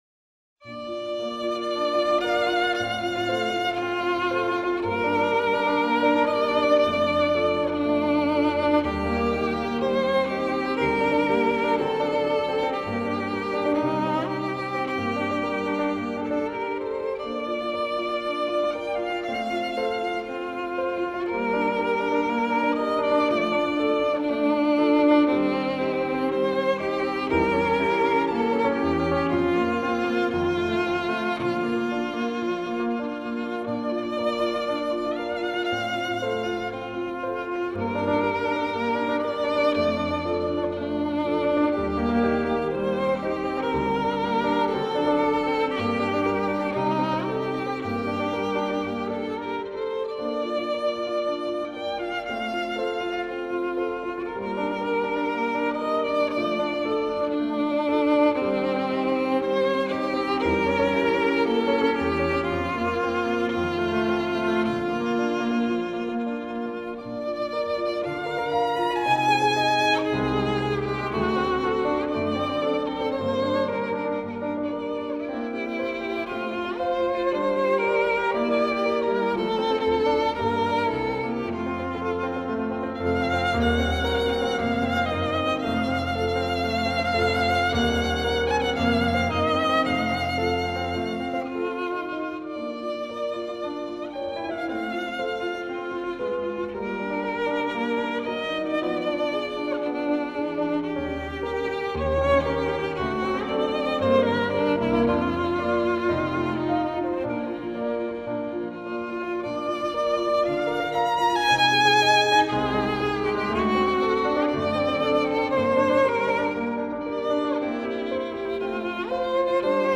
无论从演技到音质都无可挑剔，曲调优美，演奏细腻，丝丝入扣，是发烧友不可多得的一张试音天碟。